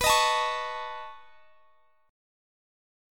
Listen to B9 strummed